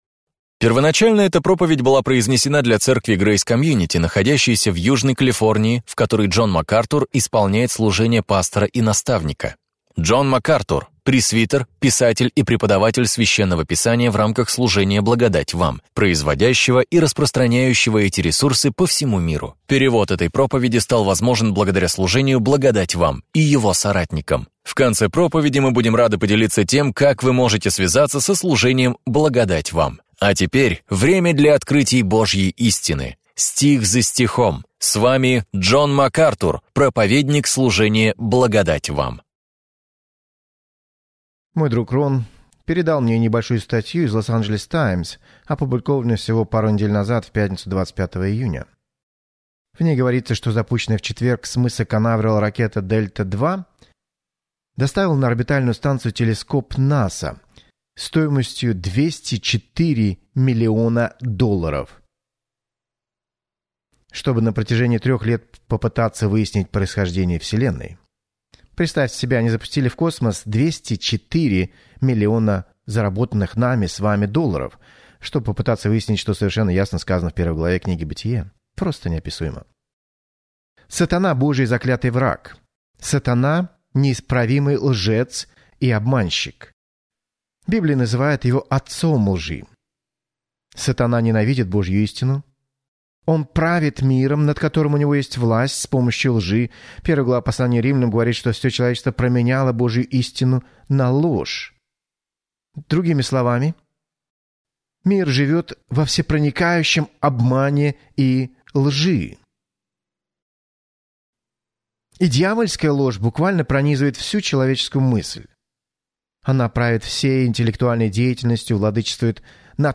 В своей проповеди «Битва за начало» Джон Макартур раскрывает суть этих споро